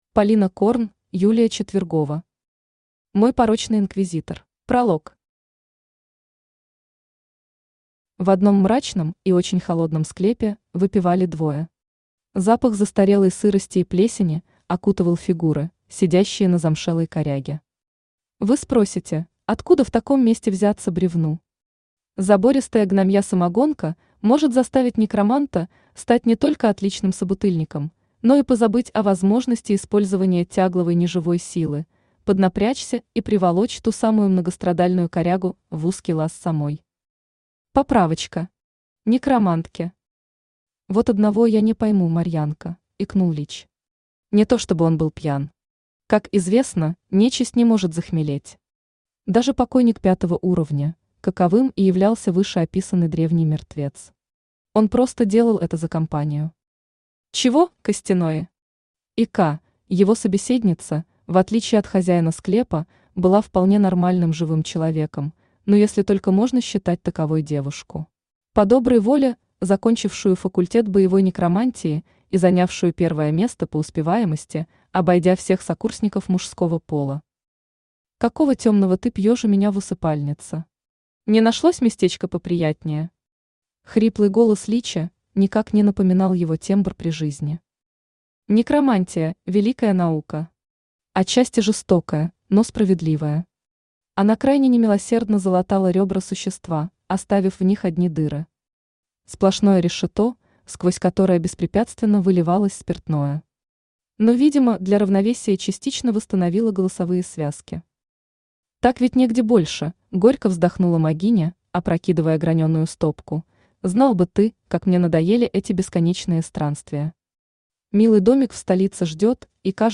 Аудиокнига Мой порочный Инквизитор | Библиотека аудиокниг
Aудиокнига Мой порочный Инквизитор Автор Полина Корн Читает аудиокнигу Авточтец ЛитРес.